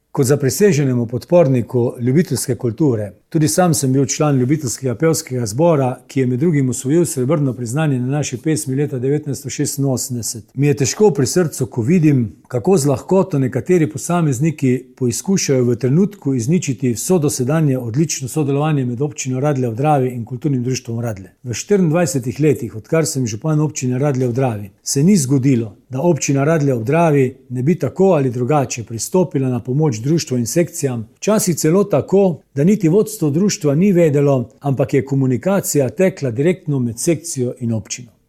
Radeljski župan Alan Bukovnik je občane nagovoril iz Doma gozdne kulture prek svojega podkasta Radgovori.